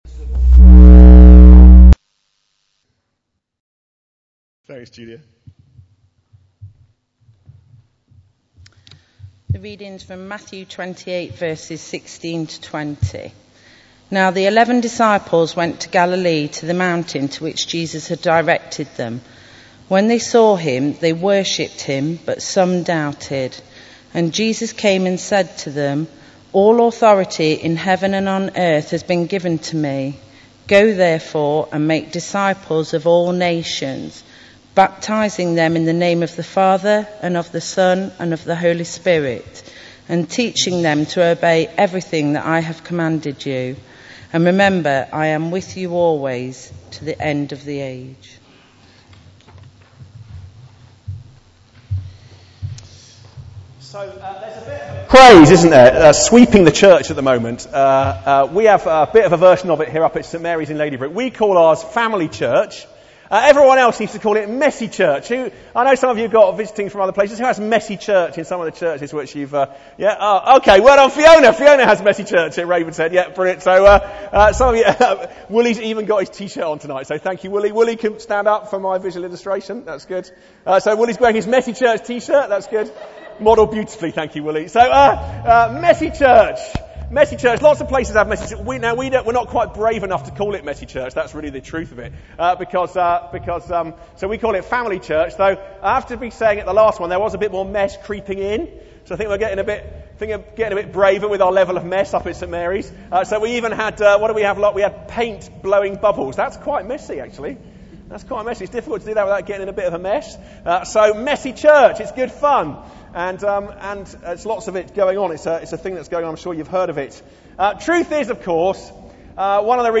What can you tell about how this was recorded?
18:00 Evening Worship, St John's service